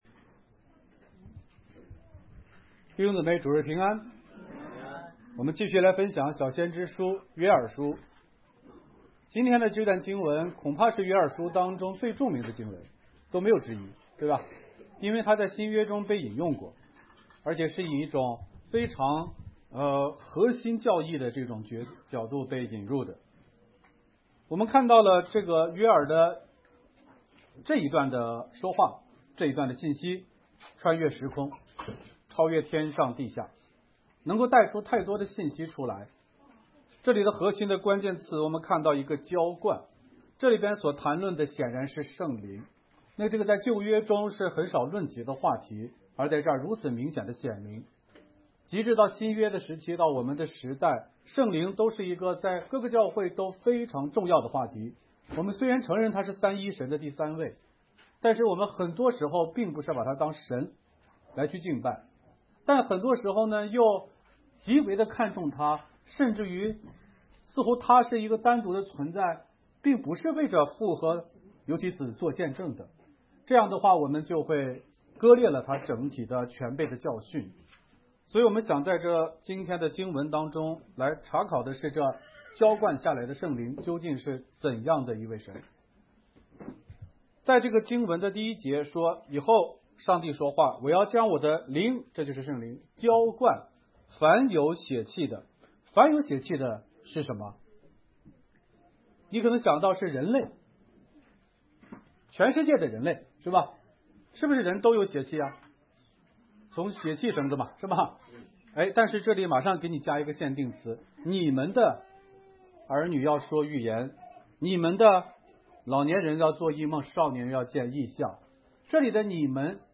讲章